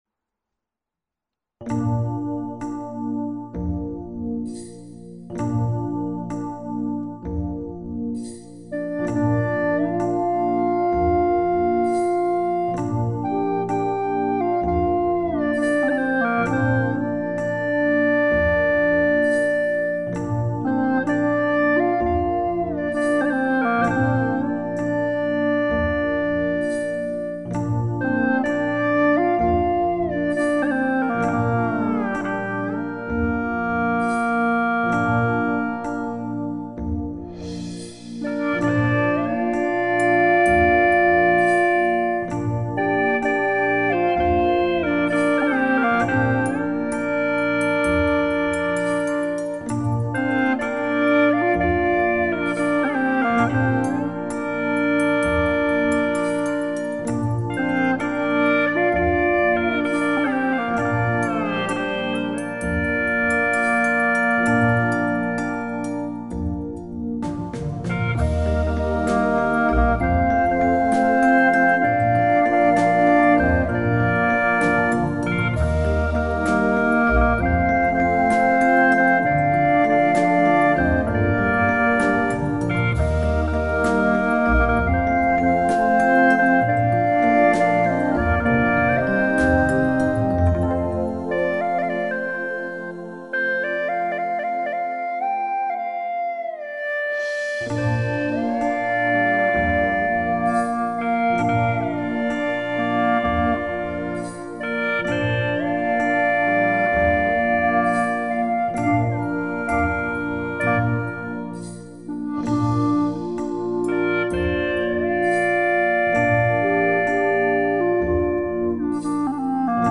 调式 : 降B
曲调缓慢、悠扬，略带哀伤。